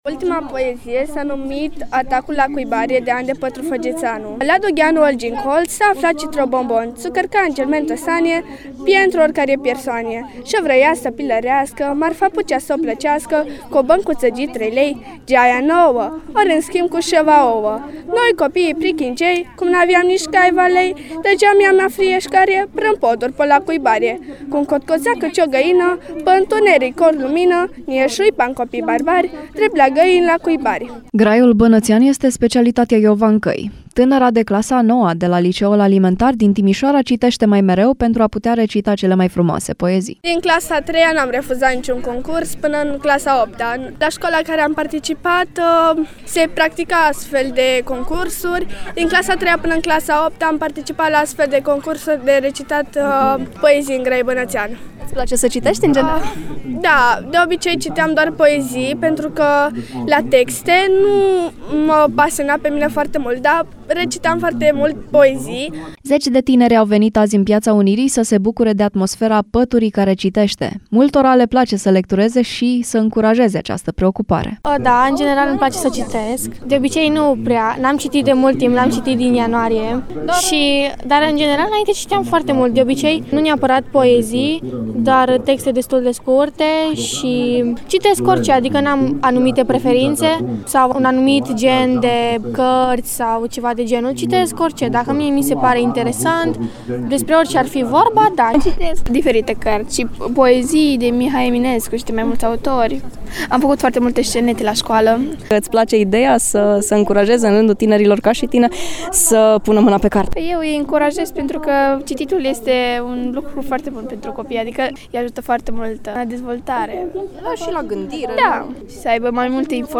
Zeci de elevi au participat astăzi la evenimentul “Pătura care citește”, în cadrul festivalului LitVest, în Timișoara.